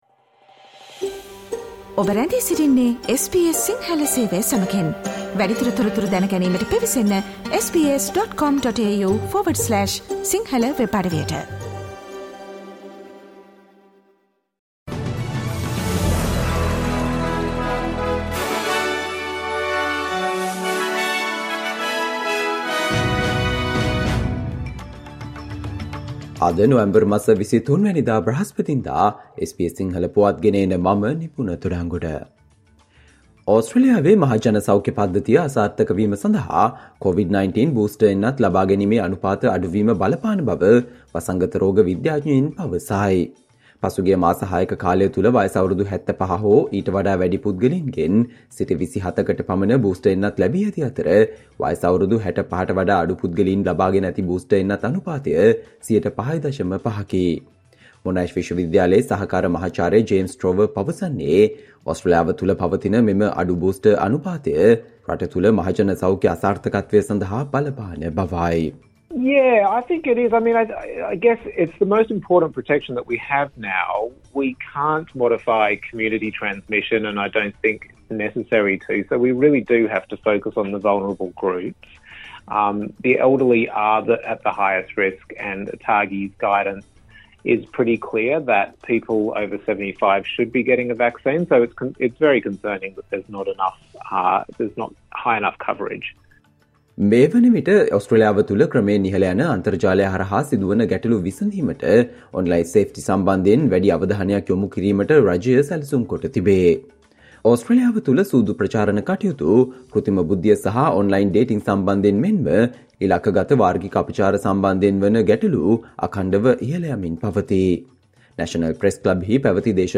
Australia news in Sinhala, foreign and sports news in brief - listen, Thursday 23 November 2023 SBS Sinhala Radio News Flash